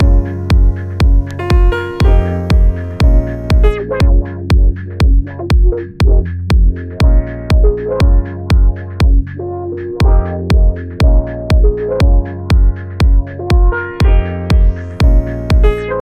Hi an issue Ive had with my machine occasionally is that a recorded sound will randomly not trigger when running the track, like for example a kickdrum will suddenly not play for one measure.
I have attached a clip of it happening (this was after the song had played for about a minute and a half without glitching).
The kick drum sample that drops out is definitely less than 2Mb so I didnt think it should be a card issue - unless the fact that the musical sample playing over it, which IS more than 2Mb, could cause a dropout on the sample less than 2mb?